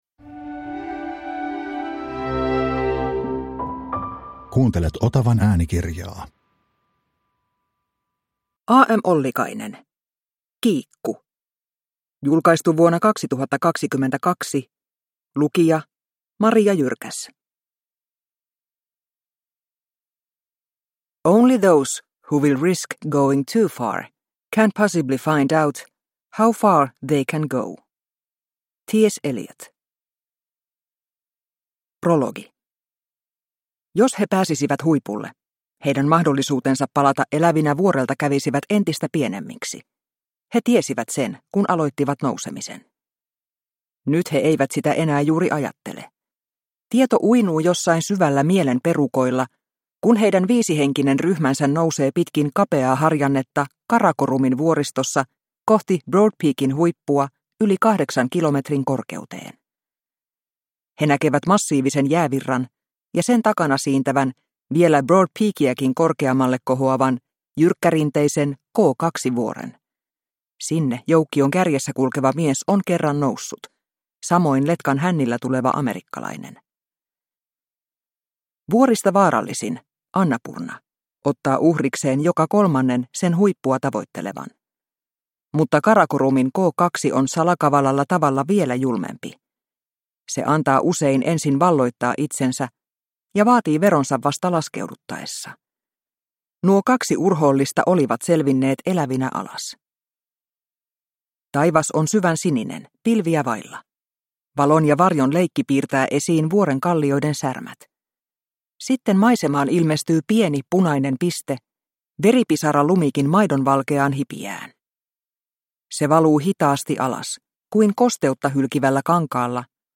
Kiikku – Ljudbok – Laddas ner